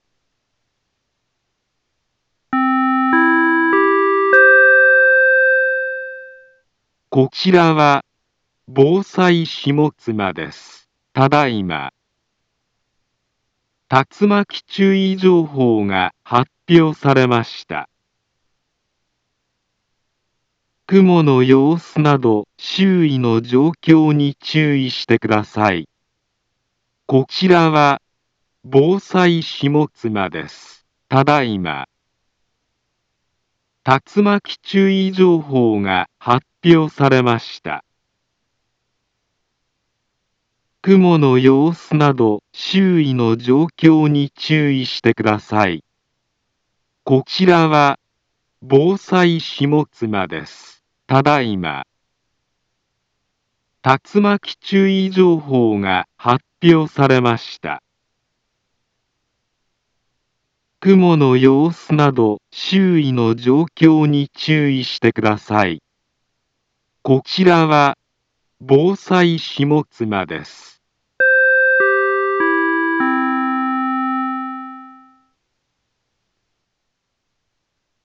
Back Home Ｊアラート情報 音声放送 再生 災害情報 カテゴリ：J-ALERT 登録日時：2025-07-01 16:28:34 インフォメーション：茨城県北部、南部は、竜巻などの激しい突風が発生しやすい気象状況になっています。